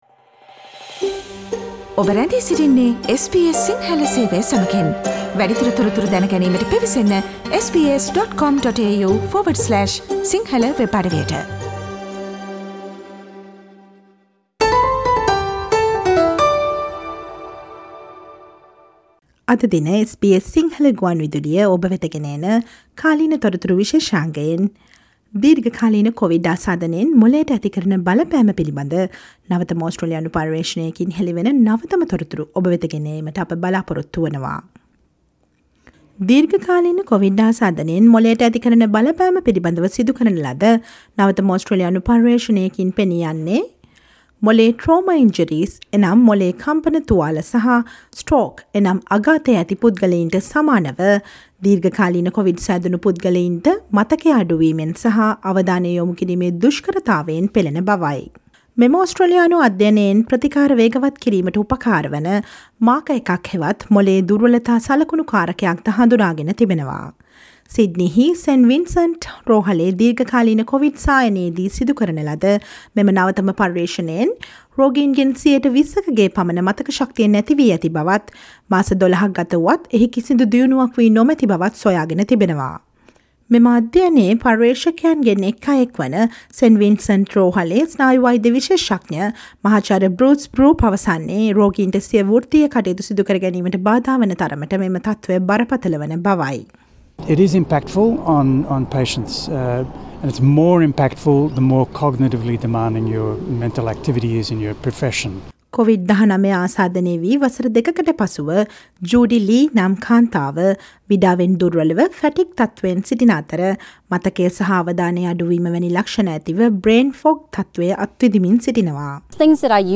දිගු කාලීන කොවිඩ් මගින් මොළයට සිදු වන බලපෑමත් එයින් පිඩා විඳින අයට ලැබුණ අලුත් බලාපොරොත්තුවත් ගැන ඔස්ට්‍රේලියාවේ නවතම පර්යේෂණයකින් හෙළි කළ තොරතුරු ගැන දැන ගන්න ජූනි 20 වන දා සඳුදා ප්‍රචාරය වූ SBS සිංහල සේවයේ කාලීන තොරතුරු විශේෂාංගයට සවන්දෙන්න.